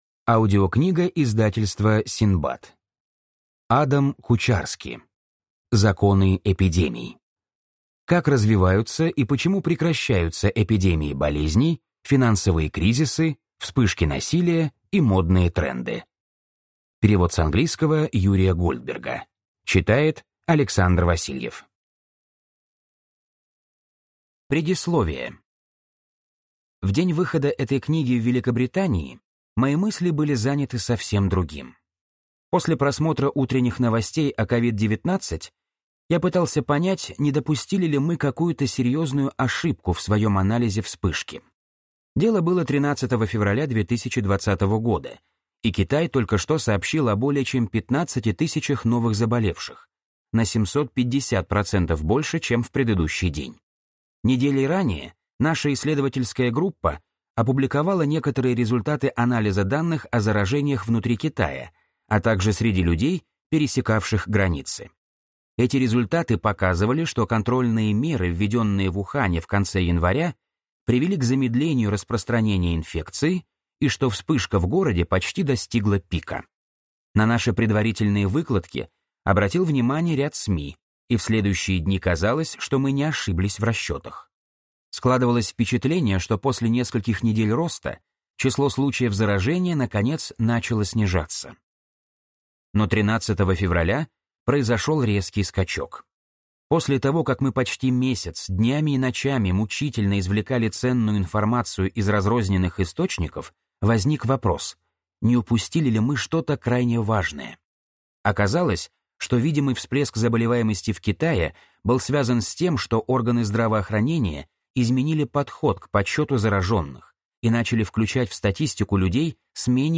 Аудиокнига Законы эпидемий. Как развиваются и почему прекращаются эпидемии болезней, финансовые кризисы, вспышки насилия и модные тренды | Библиотека аудиокниг